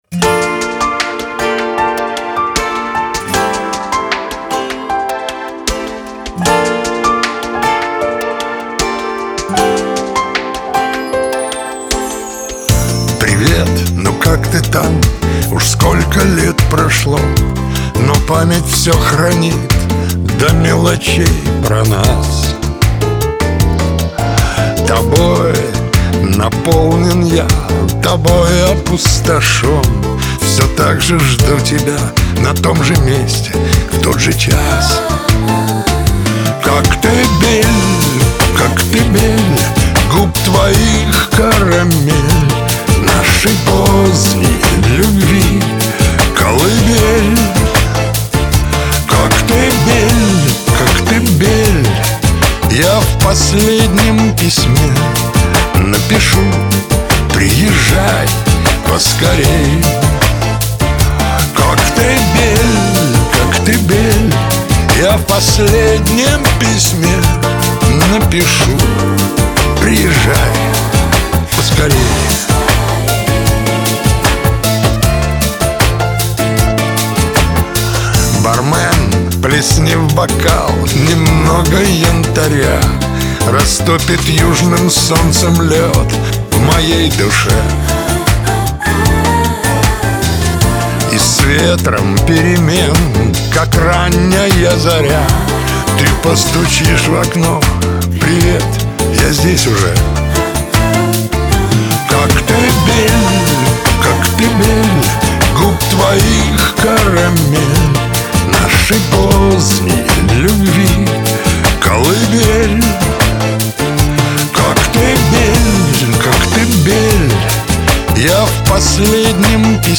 Шансон , эстрада , Лирика